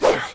slash_G_pain.ogg